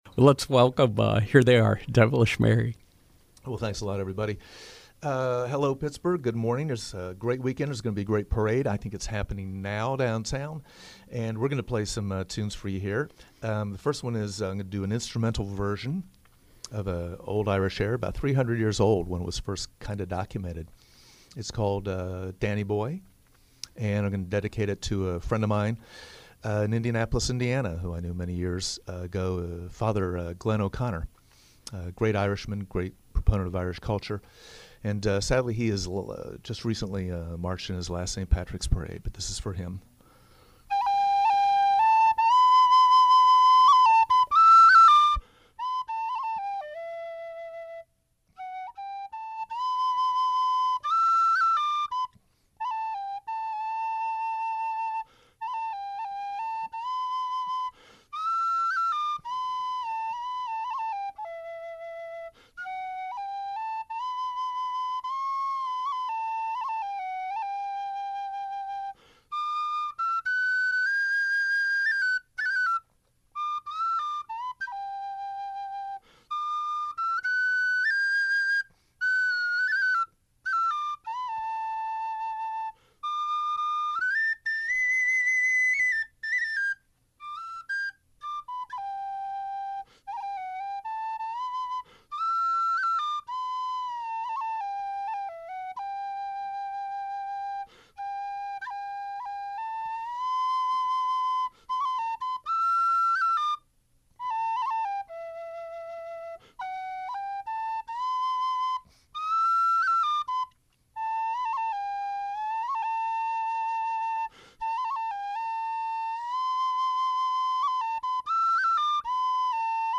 a blend of Appalachian and Celtic music